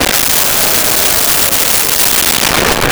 Crowd Boo Strong 01
Crowd Boo Strong 01.wav